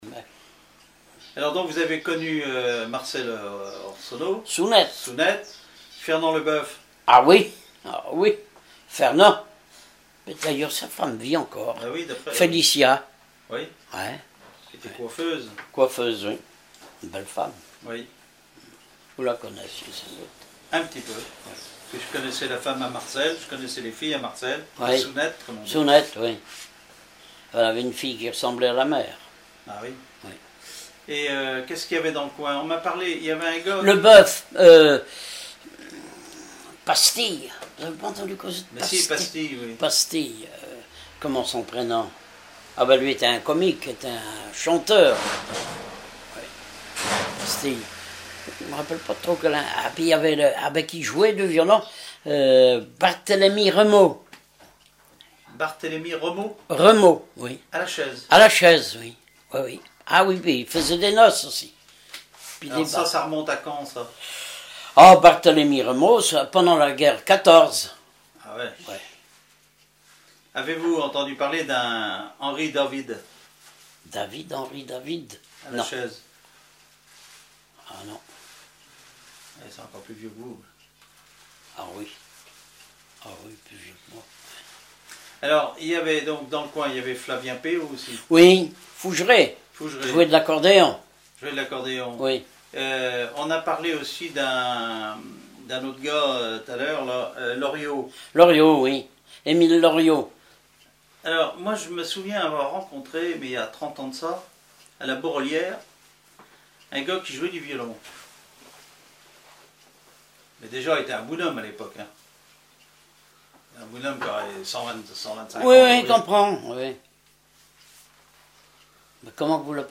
Enquête Compagnons d'EthnoDoc - Arexcpo en Vendée
Catégorie Témoignage